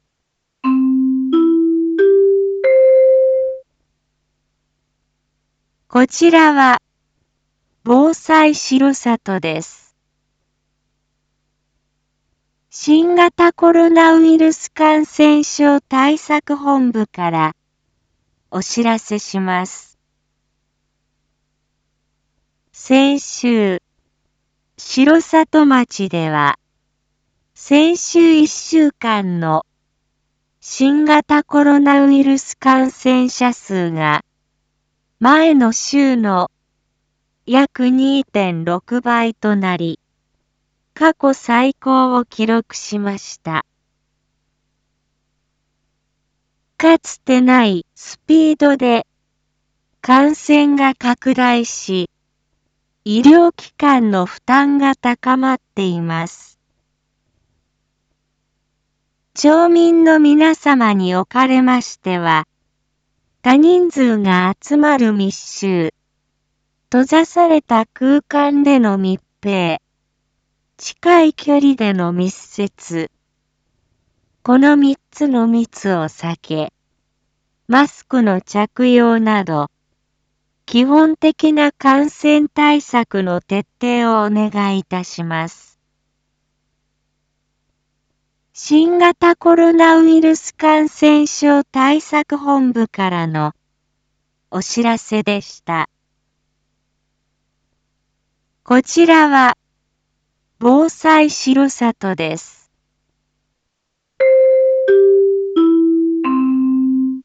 一般放送情報
Back Home 一般放送情報 音声放送 再生 一般放送情報 登録日時：2022-08-01 19:01:53 タイトル：新型コロナウイルス感染防止 インフォメーション：こちらは防災しろさとです。